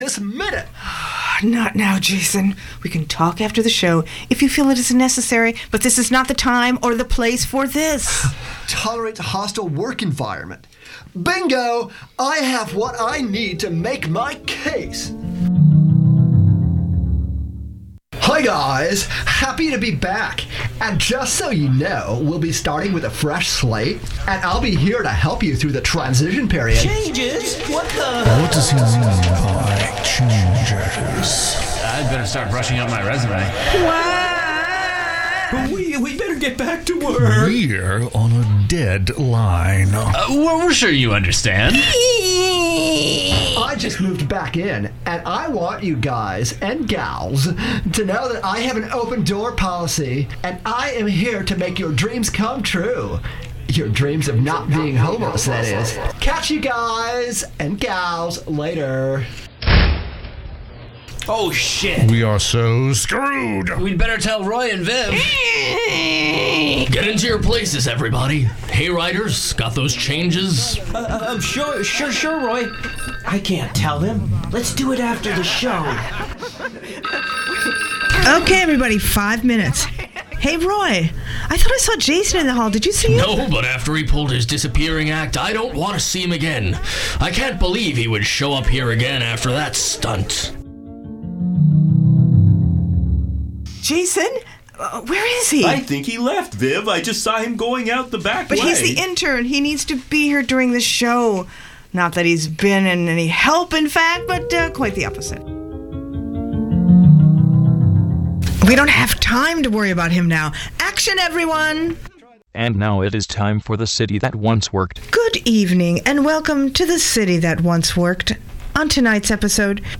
Download audio file Do we have a fabulous show for you tonight, on the first Ubu of 2014, as we continue to bring you edgy radio theater.